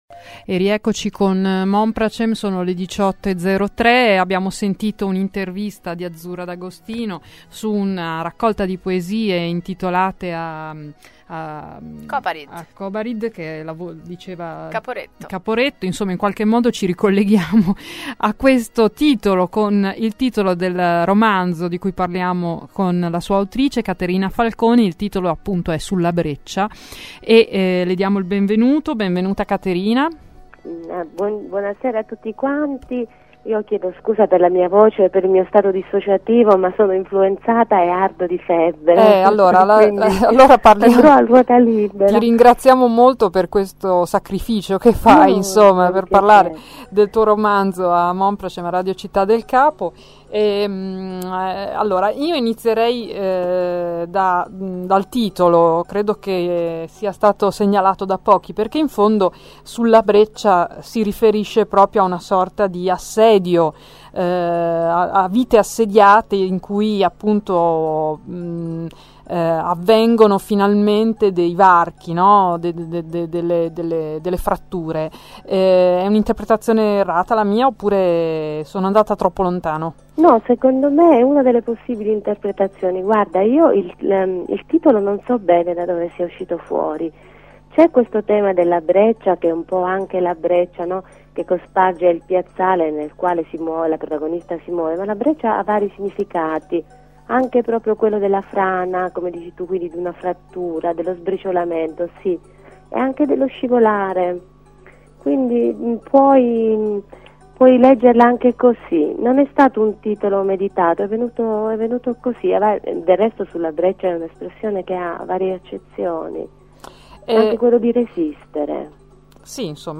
Radio e Tv
Intervista a Radio Città del Capo (novembre 2009)